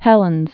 (hĕlənz)